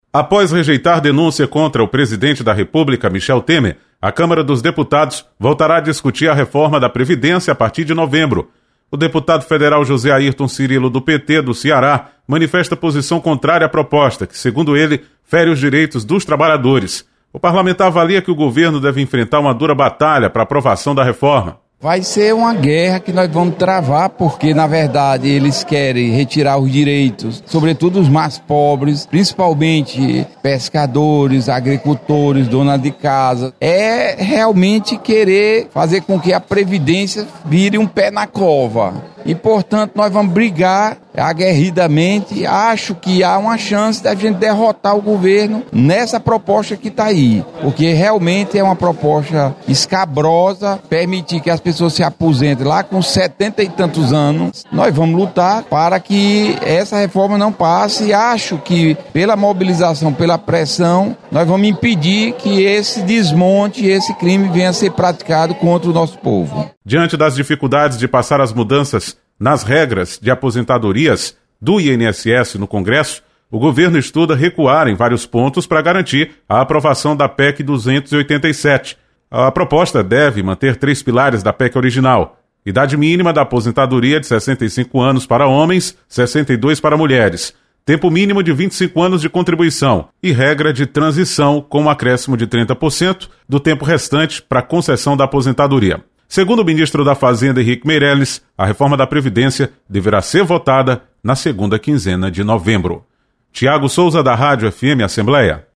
Reforma da Previdência volta à pauta de votação do Congresso em novembro. Repórter